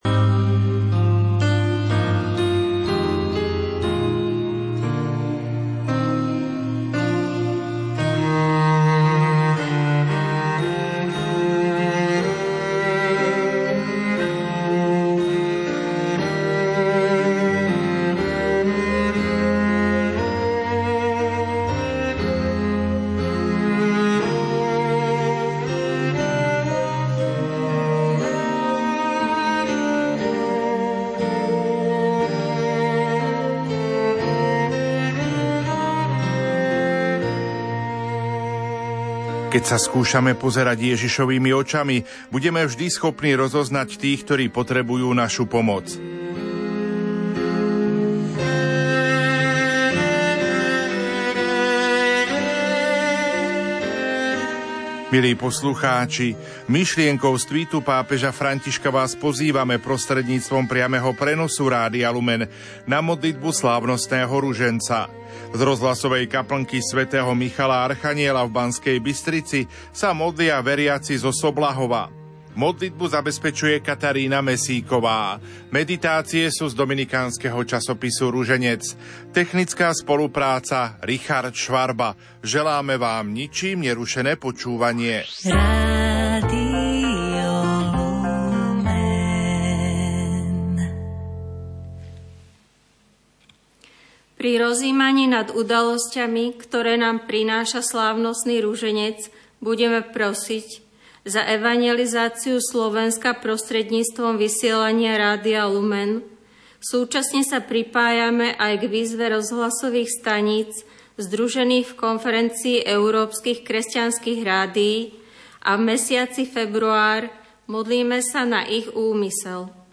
modlitba slávnostného ruženca z rádiovej kaplny sv. Michala archanjela v B.Bystrici modlia sa veriaci zo Soblahova